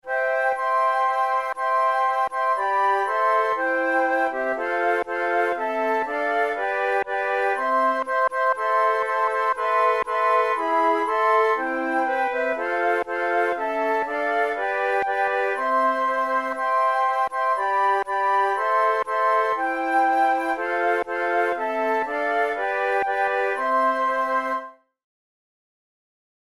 Traditional English Christmas carol, arranged for four flutes
Categories: Christmas carols Traditional/Folk Difficulty: easy